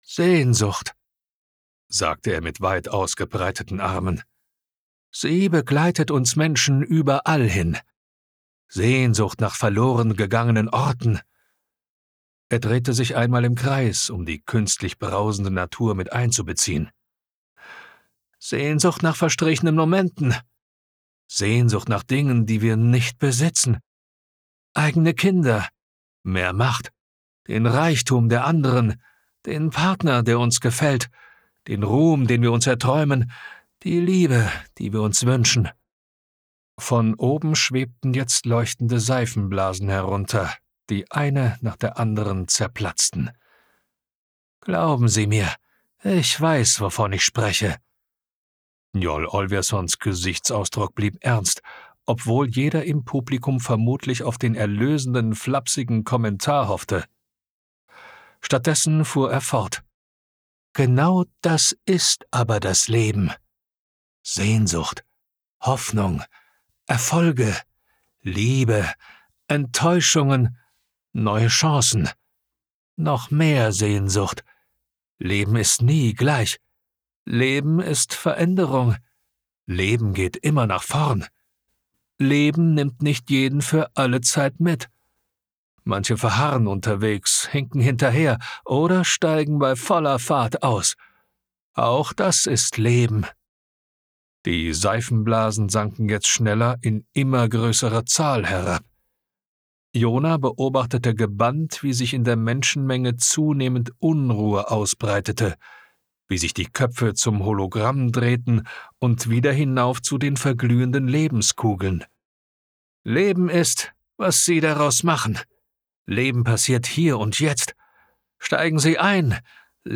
Willkommen in der Hörbuch Welt!